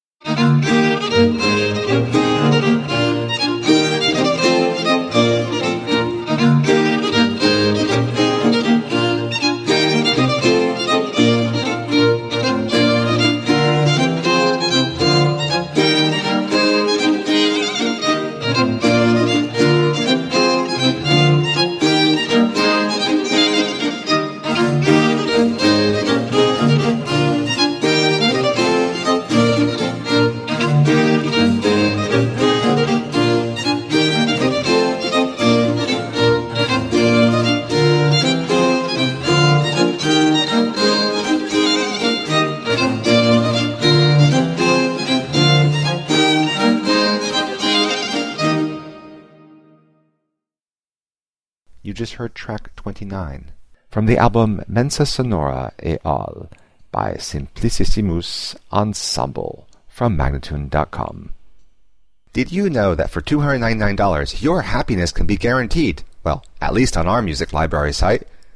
17th and 18th century classical music on period instruments
Classical, Instrumental Classical, Classical Period, Baroque